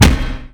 Earth_Spirit_attack2.mp3